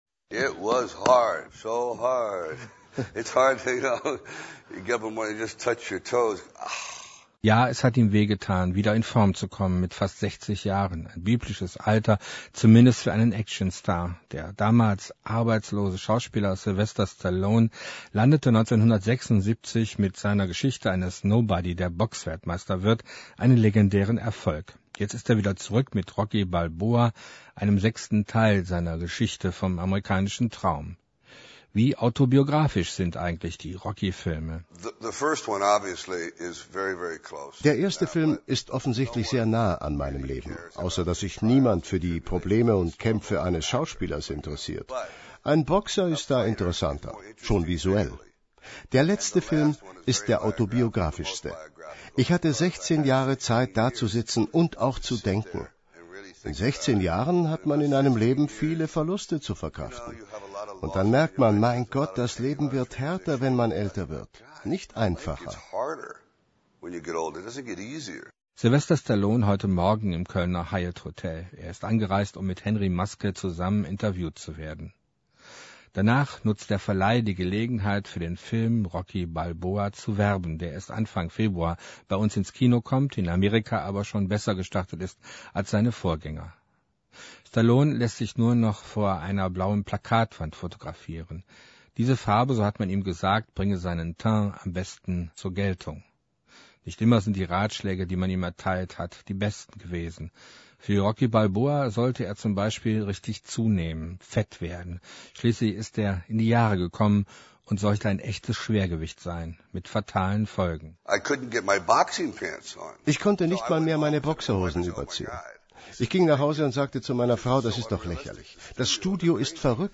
Beitrag* über Stallone, bei dem mich überrascht hat, wie respektvoll er ausgefallen ist. (Die Sendung endet außerdem, zumindest in der Fassung, die ich geladen habe, mit einem langen Ausschnitt von "Gonna Fly Now".)